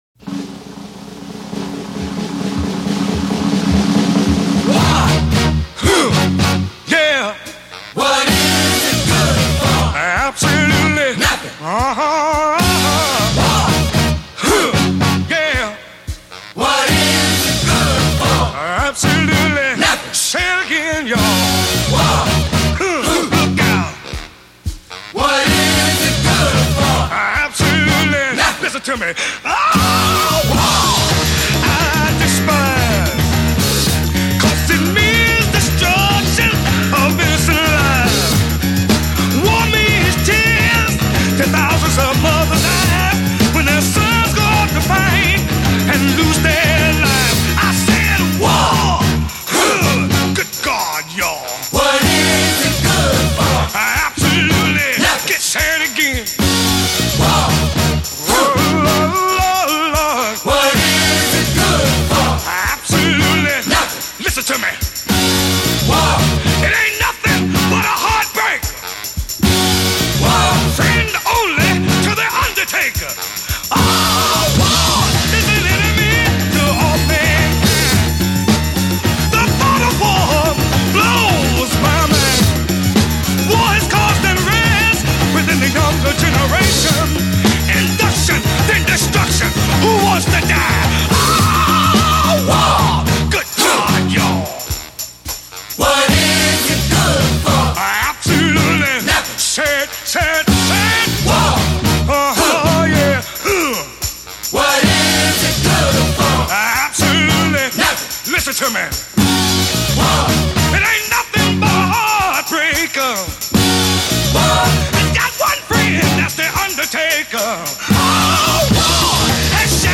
Джаз и Блюз